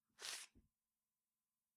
描述：这种声音是为了给人们留下一把剑从它的鞘中取出的印象，但无论如何都要用它。 我通过将两把大刀刮在一起来创造这种声音。
标签： 科幻 战争 护套 战斗 幻想 战斗 武器 中世纪 平绘制
声道立体声